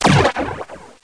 1 channel
bombex.mp3